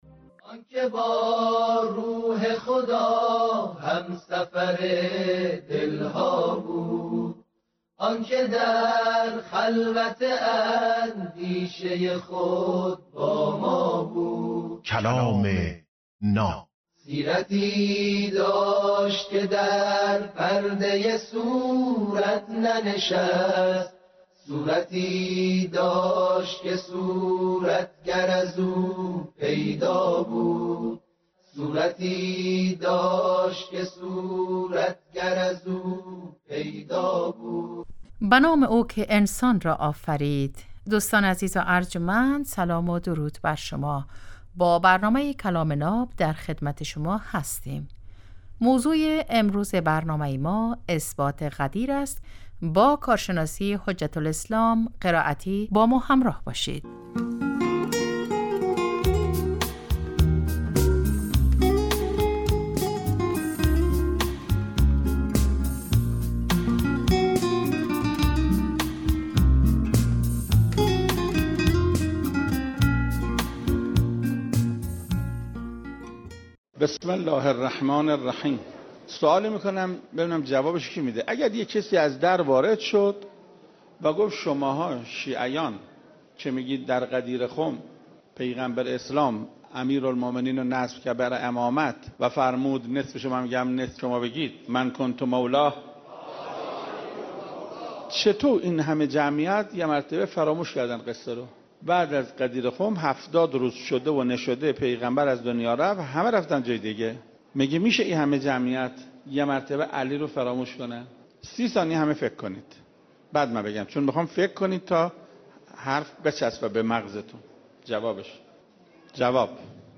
کلام ناب برنامه ای از سخنان بزرگان است که هر روز ساعت 7:35 عصر به وقت افغانستان به مدت 10دقیقه پخش می شود.